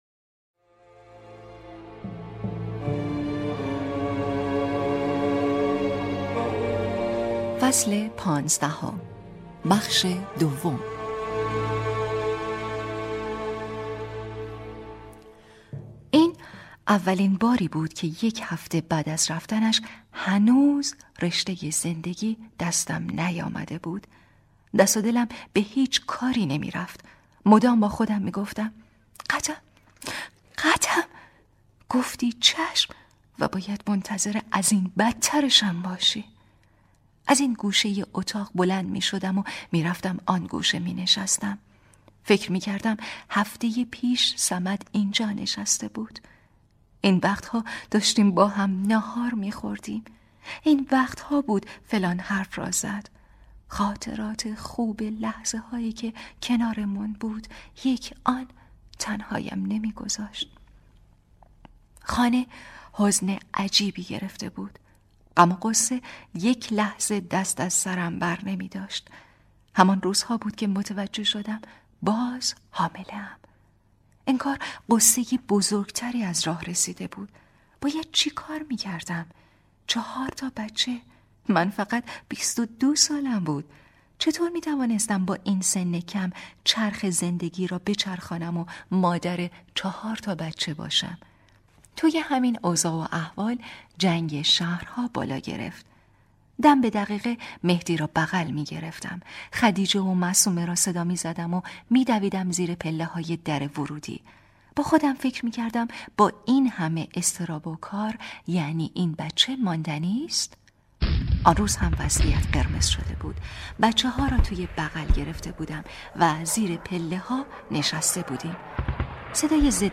کتاب صوتی | دختر شینا (14)